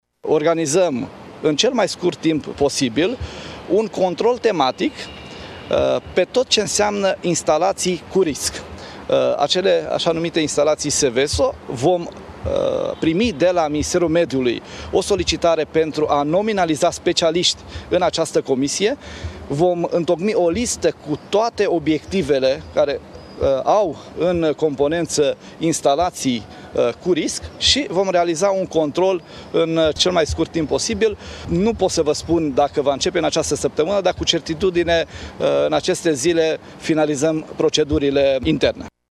Ministrul de Interne, Lucian Bode, a declarant presei că incendiul de la Azomureș a fost gestionat ”impecabil” de către salvatori, iar autoritățile și instituțiile implicate s-au mobilizat total în mai puțin de o oră.